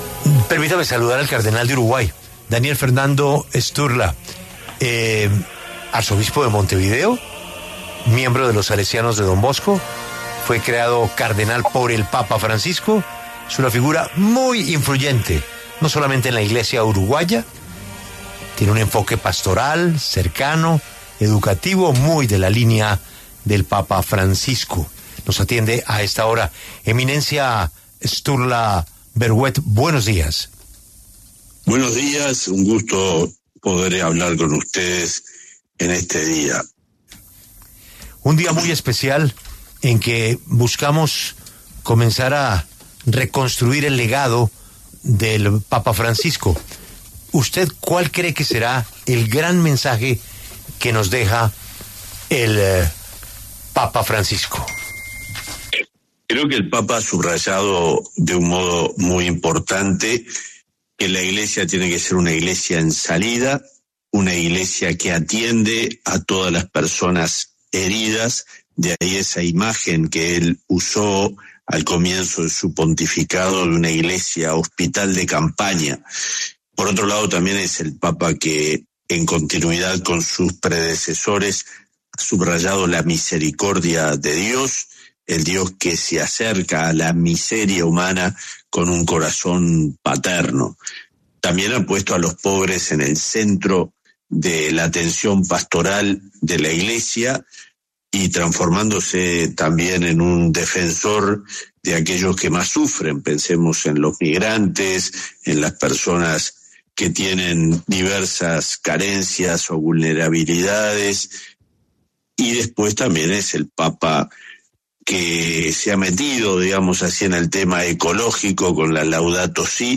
El cardenal Daniel Fernando Sturla Berhouet, de Uruguay, estuvo en La W, con Julio Sánchez Cristo, para hablar sobre la muerte del papa Francisco este 21 de abril, dejando vacante el puesto como líder de la Iglesia católica en el mundo.